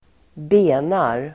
Uttal: [²b'e:nar]